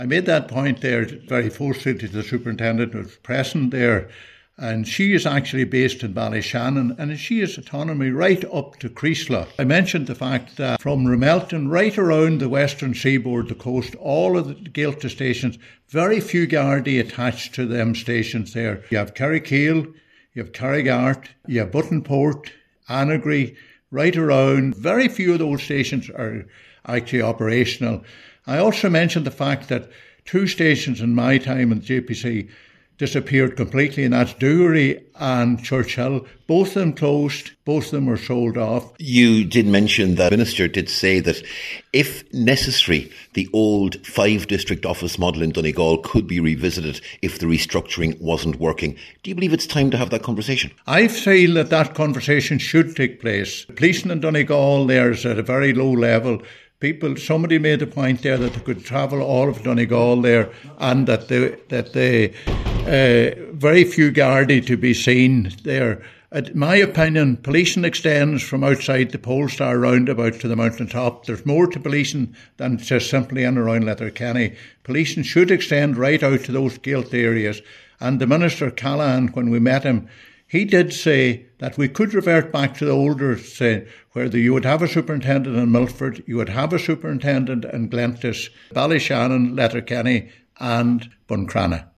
The inaugural public meeting Donegal’s Local Community Safety Partnership has heard calls for more gardai in the county.